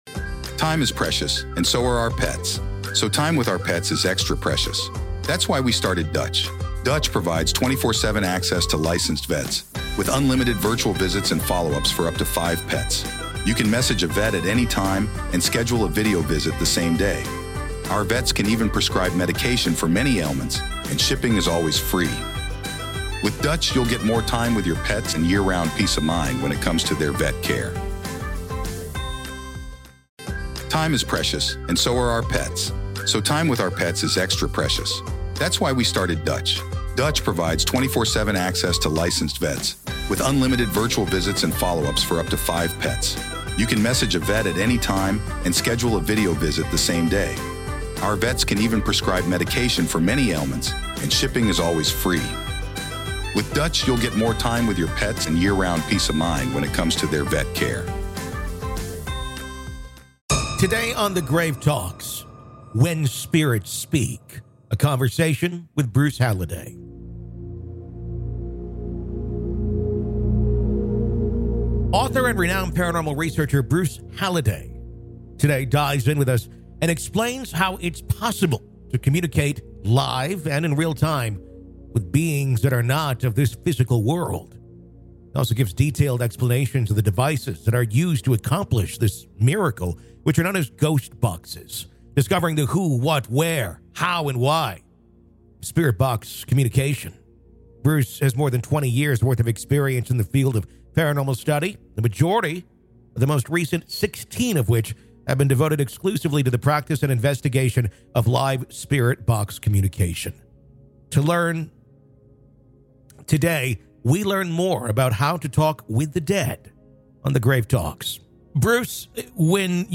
When Spirits Speak: A Conversation